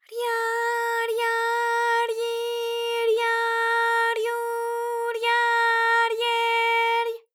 ALYS-DB-001-JPN - First Japanese UTAU vocal library of ALYS.
rya_rya_ryi_rya_ryu_rya_rye_ry.wav